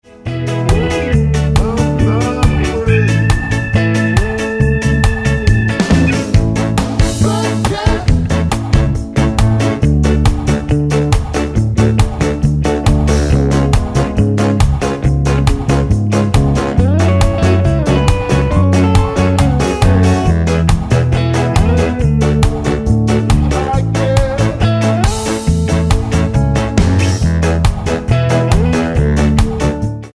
Tonart:A mit Chor
Die besten Playbacks Instrumentals und Karaoke Versionen .